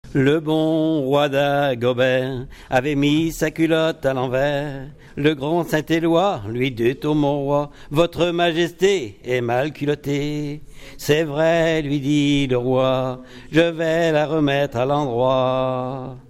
Saint-Christophe-du-Ligneron
Genre strophique
Pièce musicale inédite